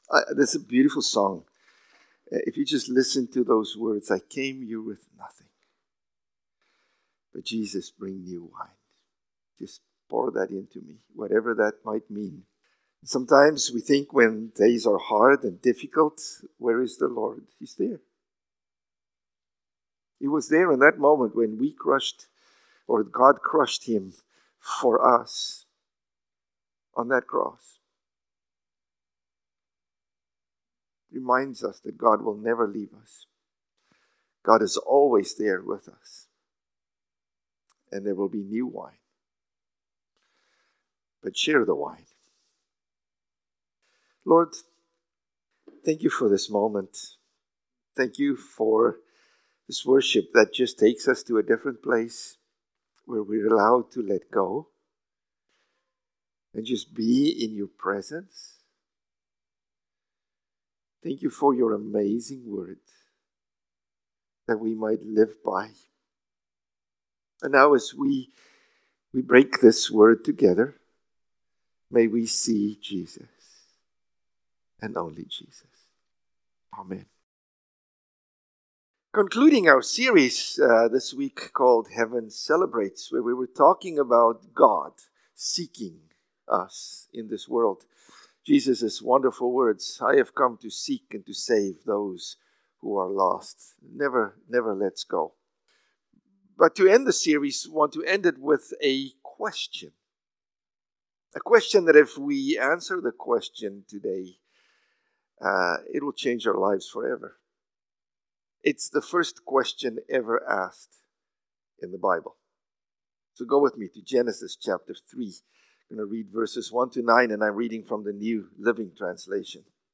March-9-Sermon.mp3